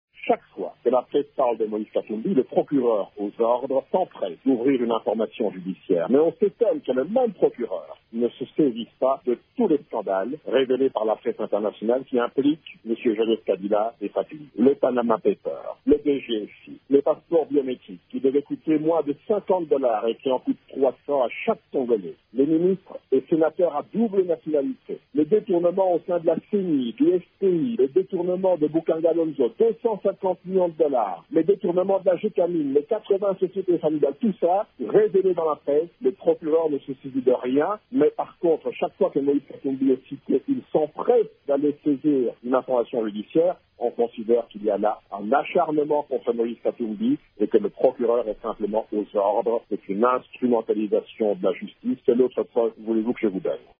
En réaction, le porte-parole de Moise Katumbi, Olivier Kamitatu, dénonce un acharnement contre le président de la plateforme « Ensemble pour le changement » :